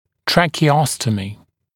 [ˌtrækə’ɔstəmɪ][ˌтрэкэ’остэми]трахеостомия
tracheostomy.mp3